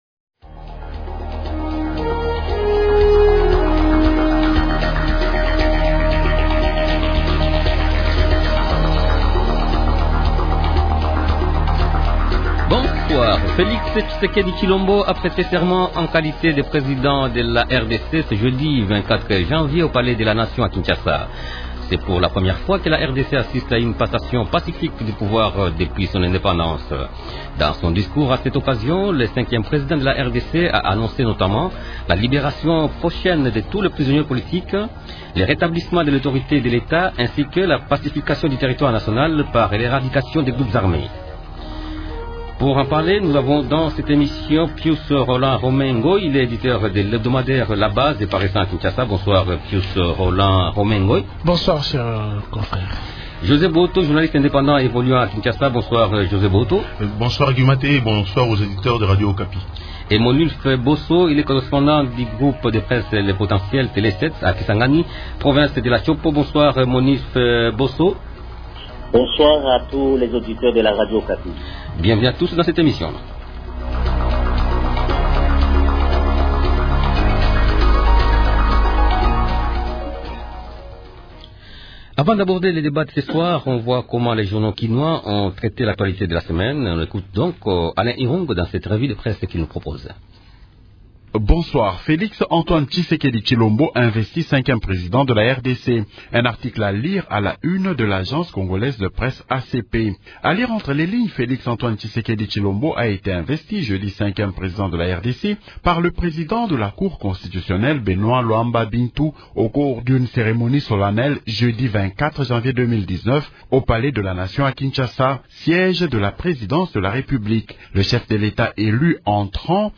Journaliste indépendant.